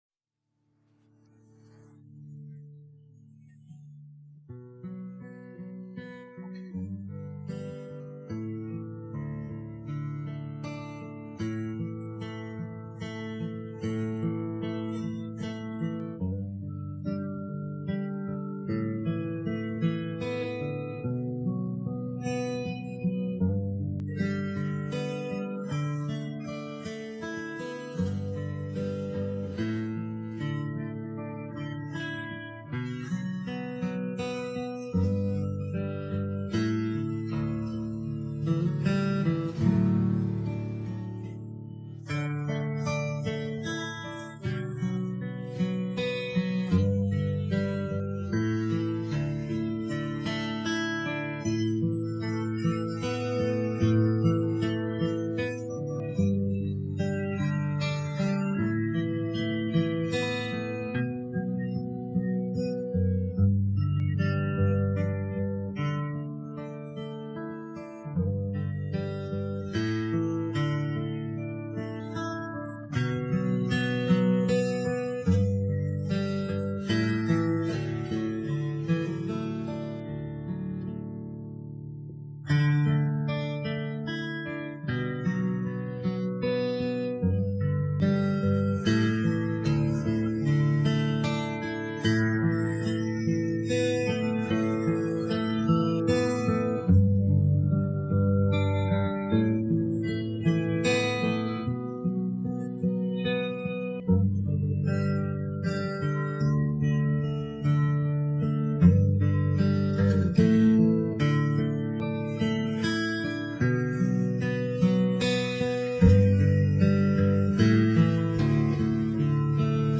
separated guitar version sounds pretty good.